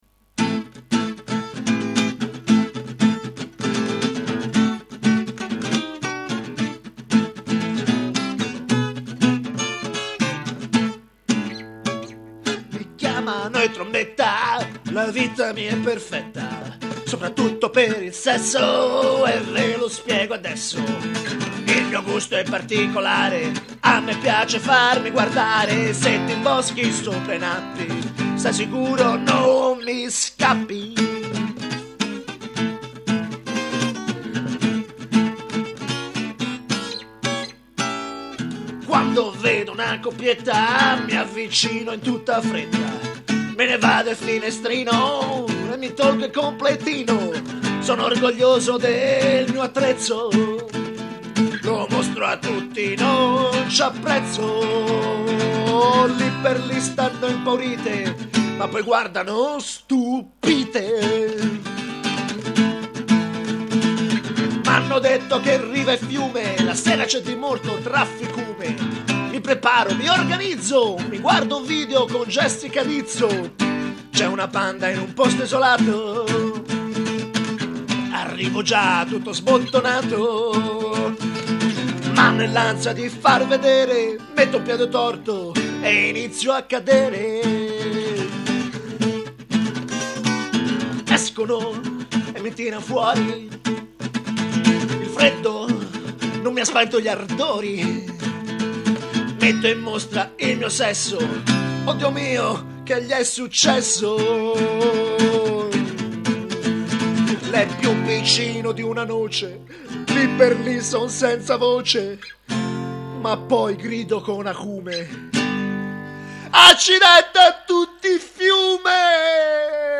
un pezzo piu' o meno funky (provate voi a fare funky con la chitarra acustica!